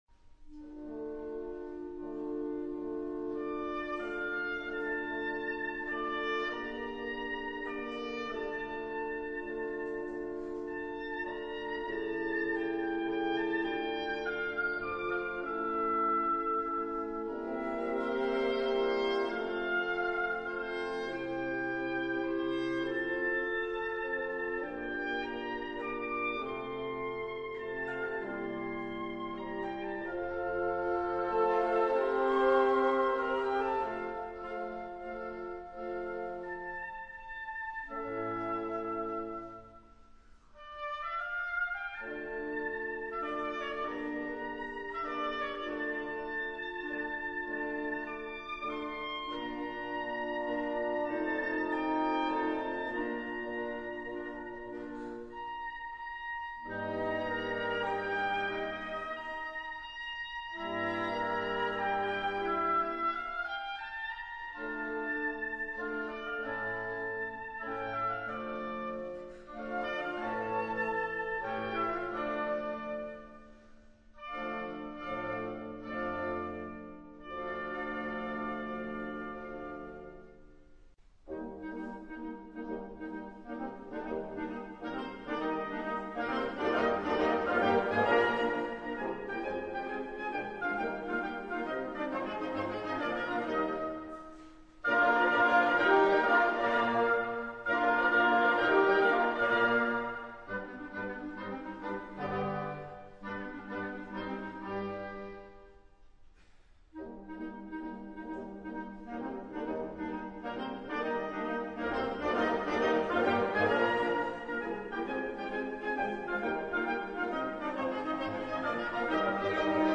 Per doppio quintetto di fiati con contrabbasso (ad lib.)